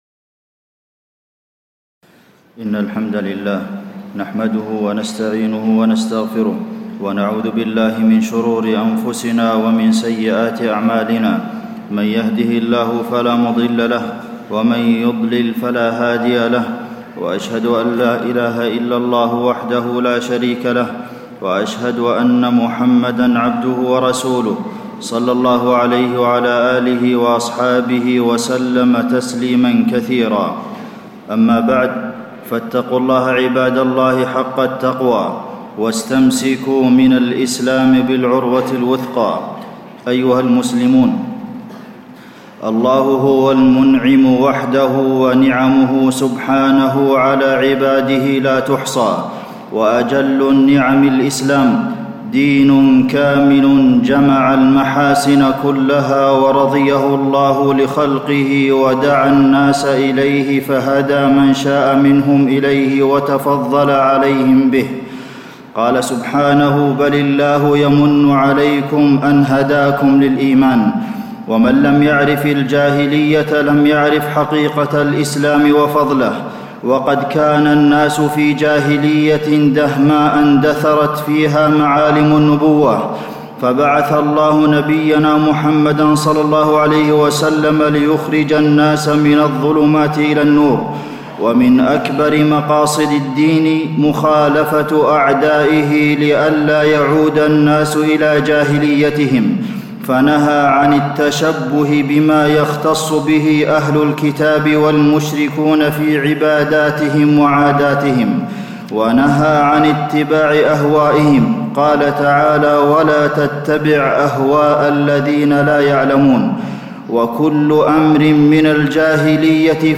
تاريخ النشر ٦ شعبان ١٤٣٧ هـ المكان: المسجد النبوي الشيخ: فضيلة الشيخ د. عبدالمحسن بن محمد القاسم فضيلة الشيخ د. عبدالمحسن بن محمد القاسم مخالفة أفعال الجاهلية